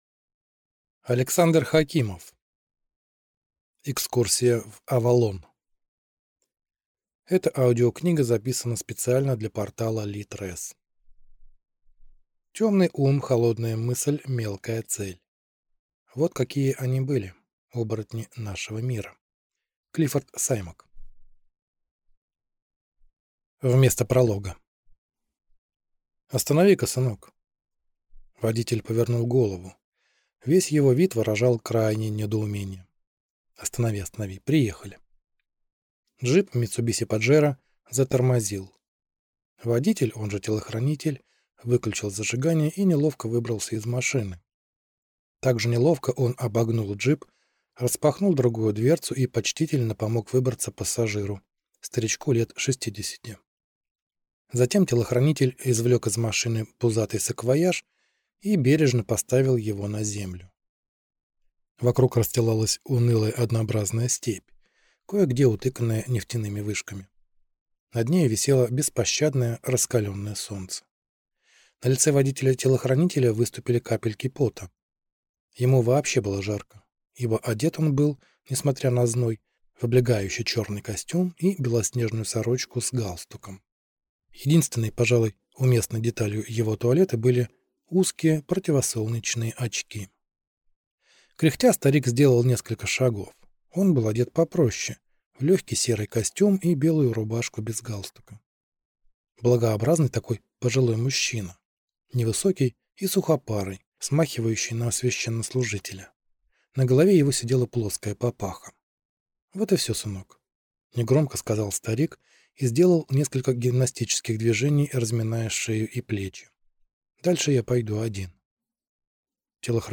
Аудиокнига Экскурсия в Авалон | Библиотека аудиокниг